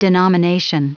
Prononciation du mot denomination en anglais (fichier audio)
Prononciation du mot : denomination